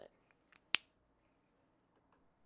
描述：戴上标记盖